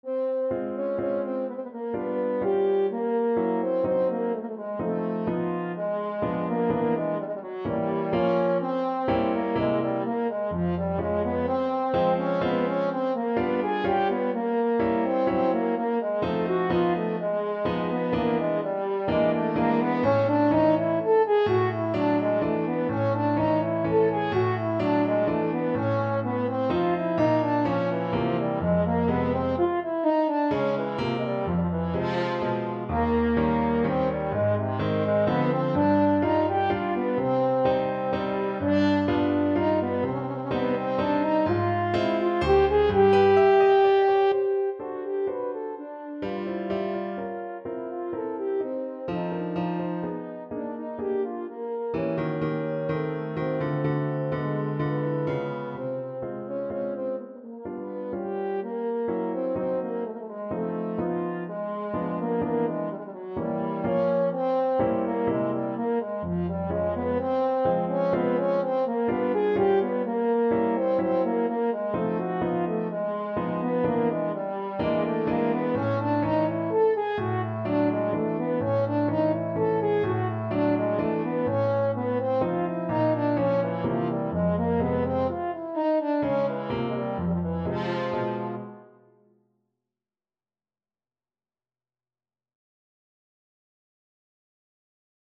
Classical Chopin, Frédéric Mazurka Op. 68 No. 4 French Horn version
Play (or use space bar on your keyboard) Pause Music Playalong - Piano Accompaniment Playalong Band Accompaniment not yet available transpose reset tempo print settings full screen
French Horn
Ab major (Sounding Pitch) Eb major (French Horn in F) (View more Ab major Music for French Horn )
Andantino =126 (View more music marked Andantino)
3/4 (View more 3/4 Music)
Classical (View more Classical French Horn Music)